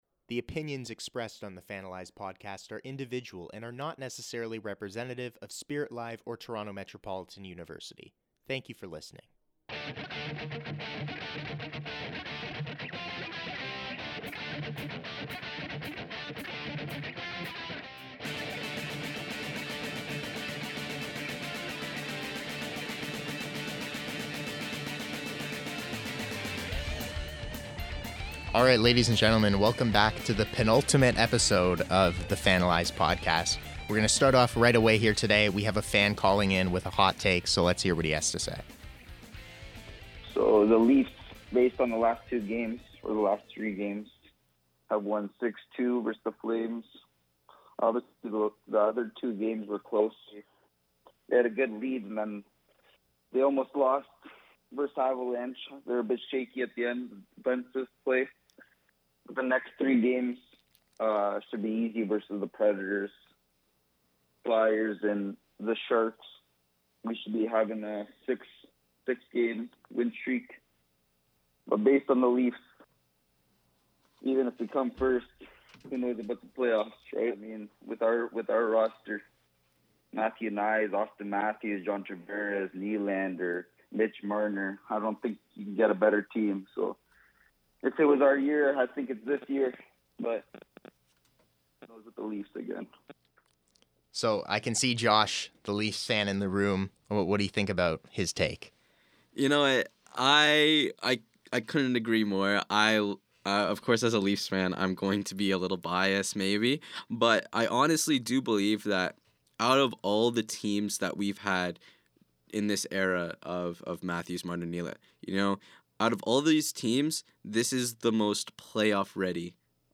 A caller offers his take on the the Toronto Maple Leafs playoff hopes. We discuss the topic and cover where there is both reason for excitement and cause for concern. March Madness is upon us, we share our picks and predictions in our bracket challenge.